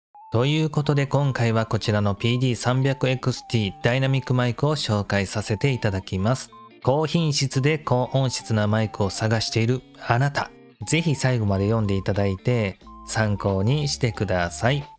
スタンド付きのダイナミックマイク！